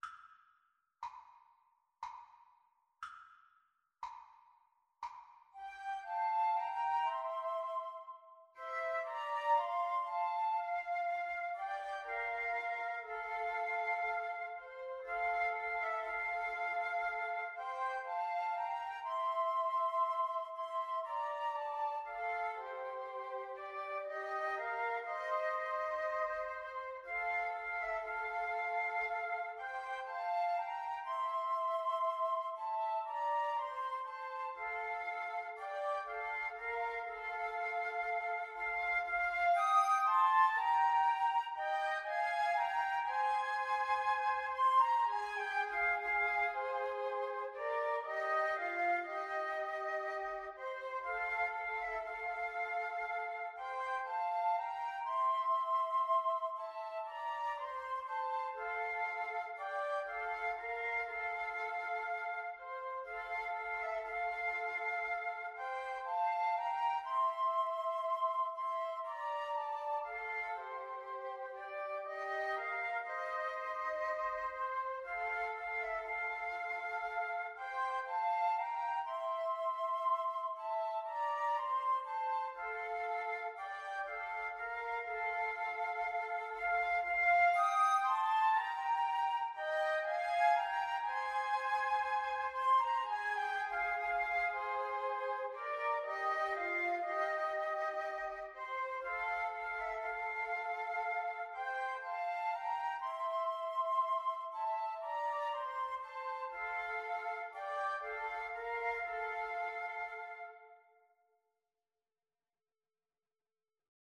3/4 (View more 3/4 Music)
Slow, expressive =c.60